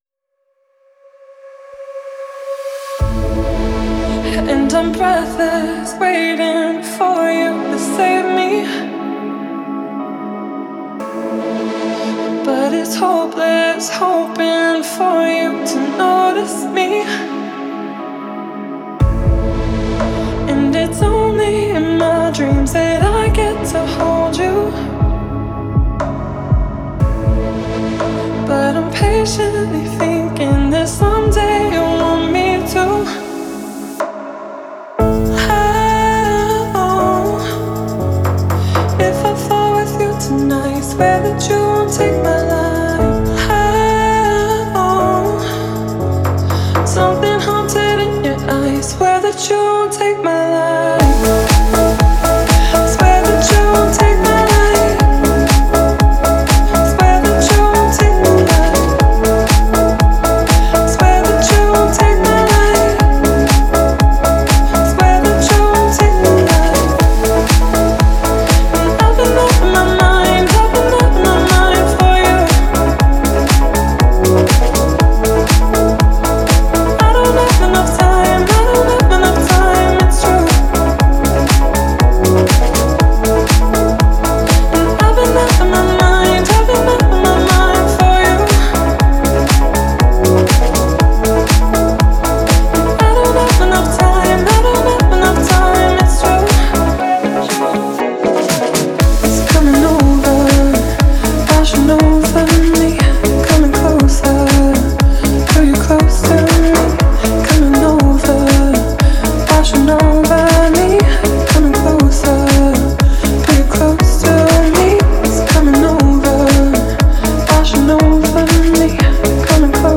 это яркая и мелодичная композиция в жанре EDM
плавными синтезаторами и ритмичными битами